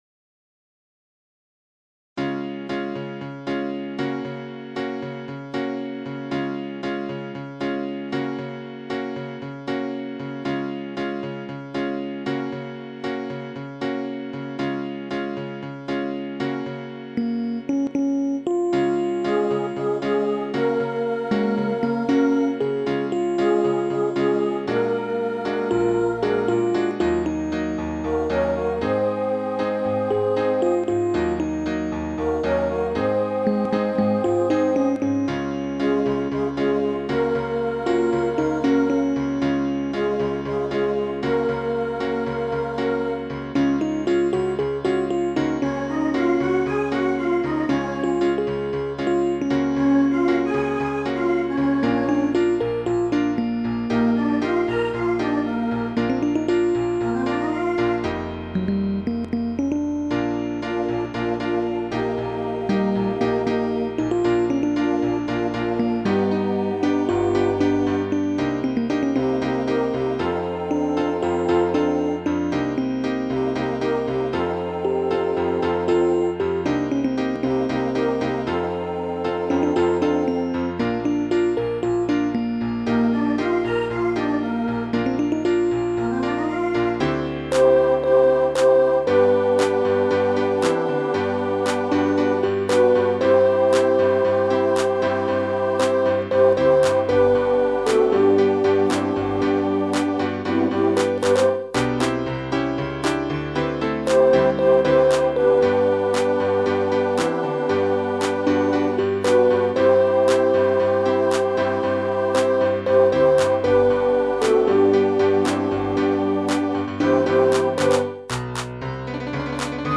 伴奏はピアノだけにして全体的に劇中挿入曲のイメージを尊重した。